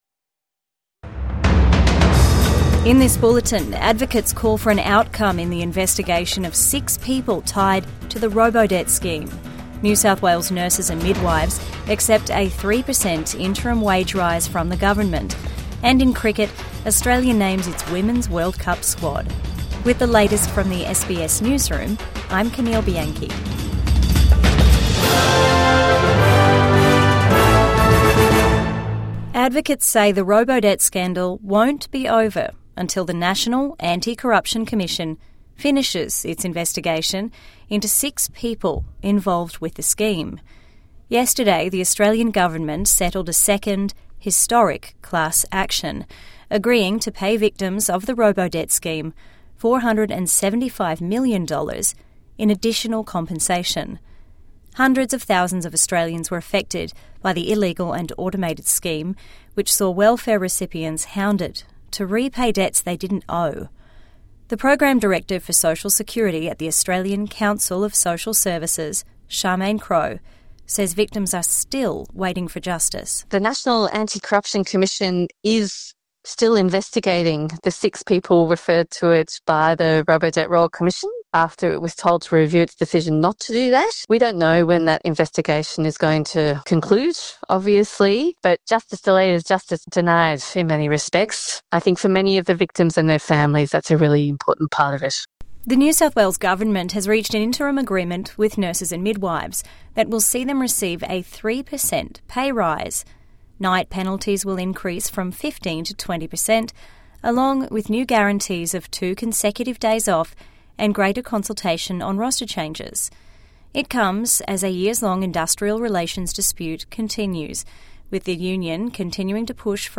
Calls for justice in ongoing Robodebt investigation | Midday News Bulletin 5 September 2025